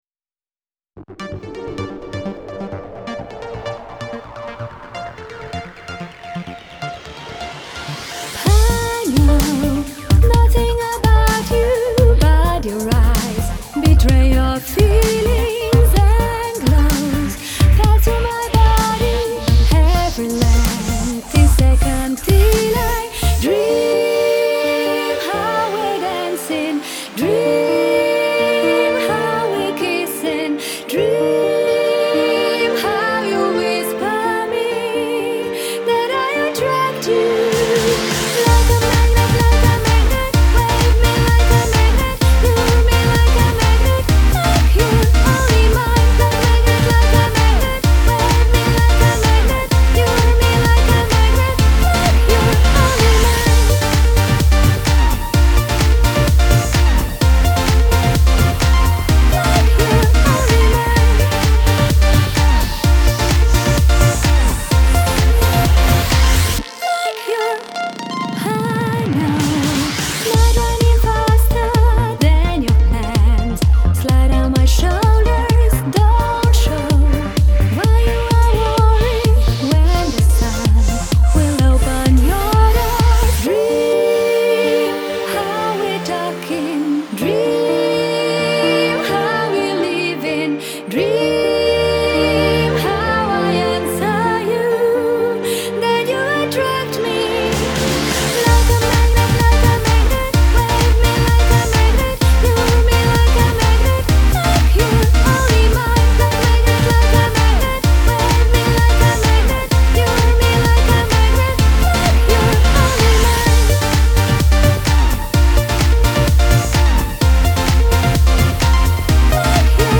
Iz zabavne-pop glasbe  v  Progressive house